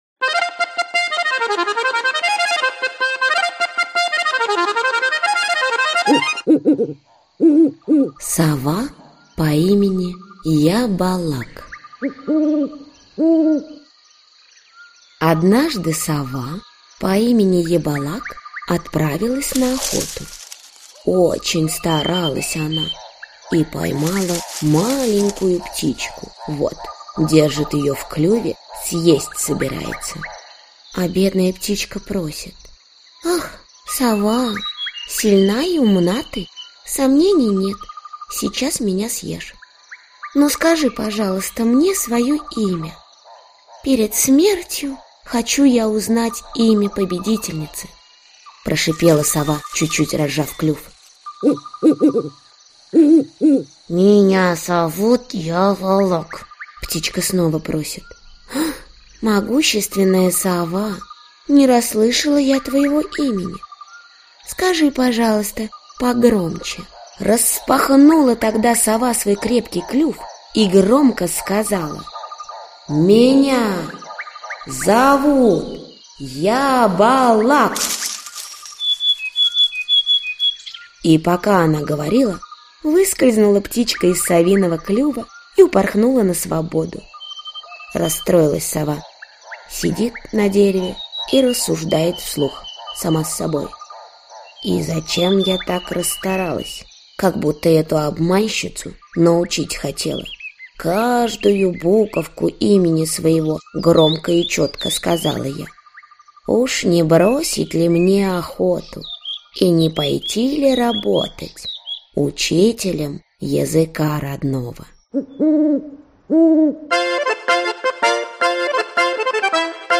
Аудиокнига Камыр-Батыр. Татарские народные сказки | Библиотека аудиокниг
Татарские народные сказки Автор Группа авторов Читает аудиокнигу Чулпан Хаматова.